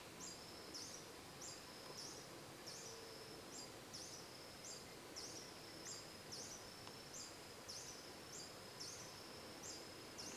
Ermitaño Escamado (Phaethornis eurynome)
Nombre en inglés: Scale-throated Hermit
Localidad o área protegida: Bio Reserva Karadya
Condición: Silvestre
Certeza: Vocalización Grabada
ermitano-escamado.mp3